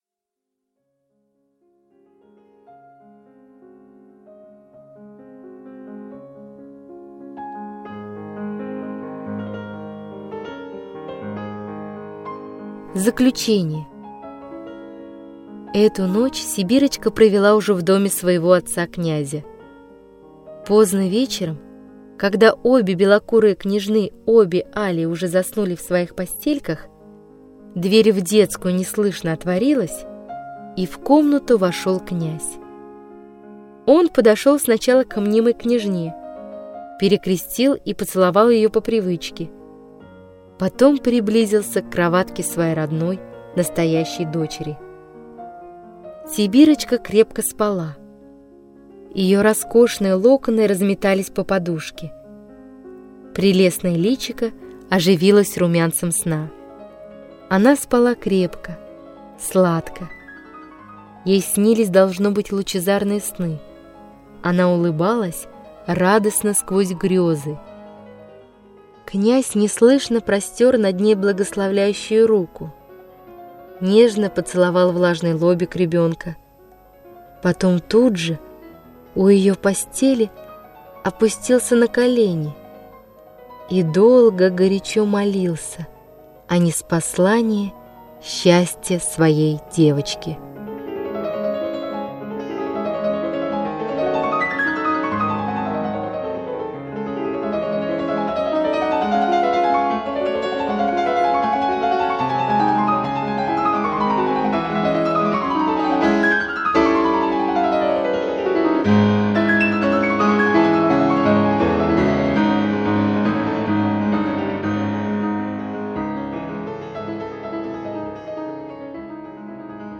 Аудиокнига Сибирочка | Библиотека аудиокниг